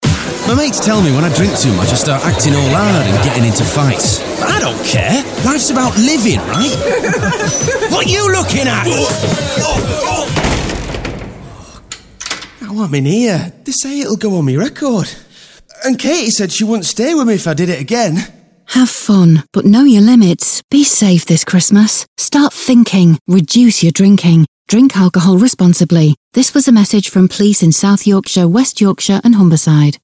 Be Safe this Christmas - Alcohol Related Violence Radio Ad